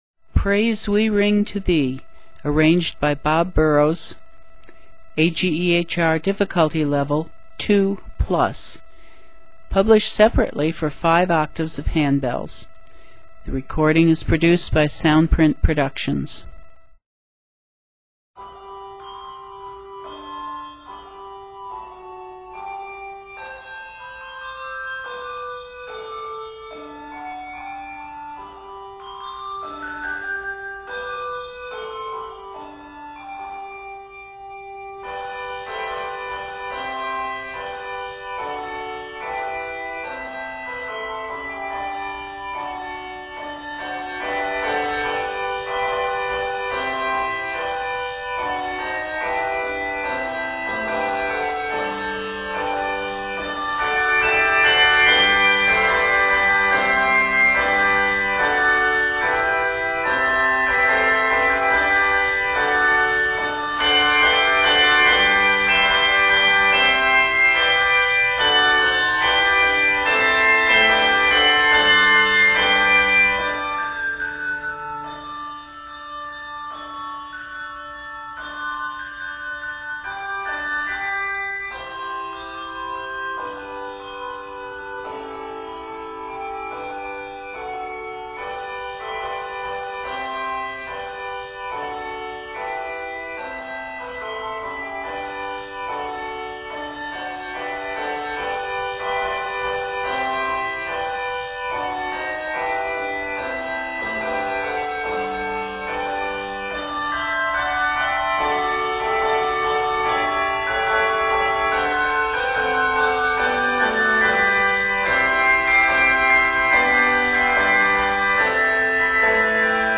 Set in G Major, measures total 46.
Octaves: 5